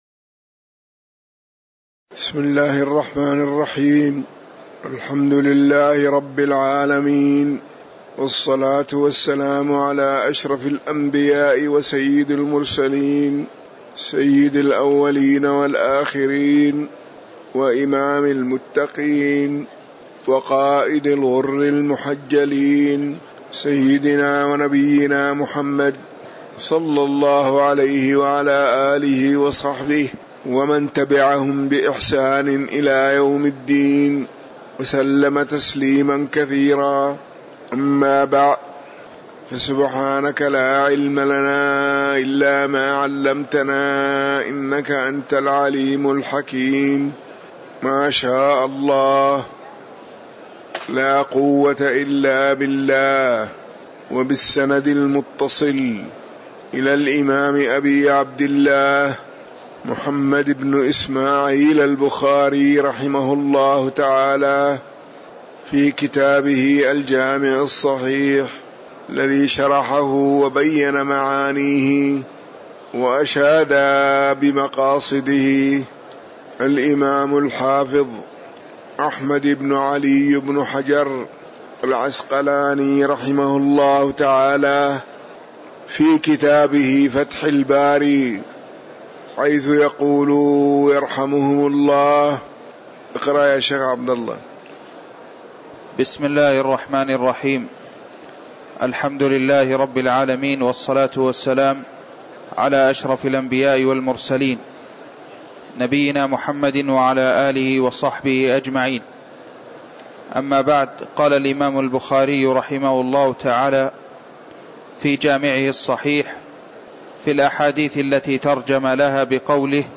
تاريخ النشر ٣٠ ربيع الأول ١٤٤١ هـ المكان: المسجد النبوي الشيخ